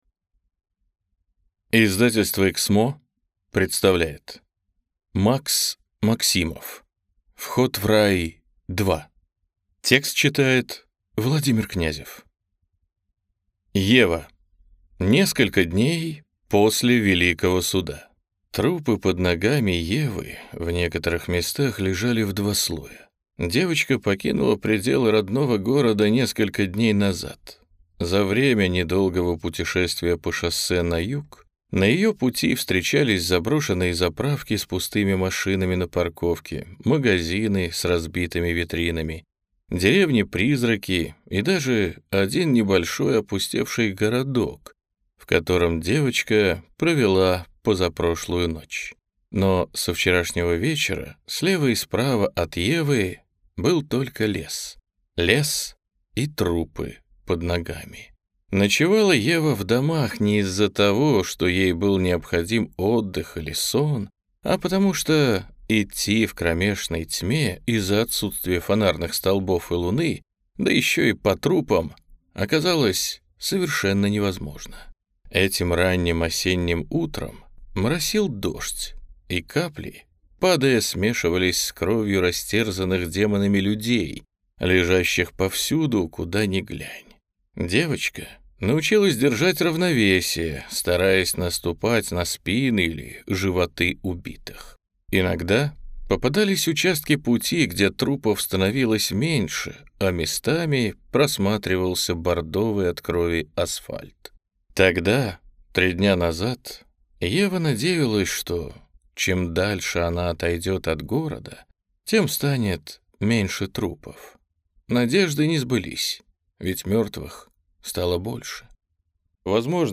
Аудиокнига Вход в рай 2 | Библиотека аудиокниг
Прослушать и бесплатно скачать фрагмент аудиокниги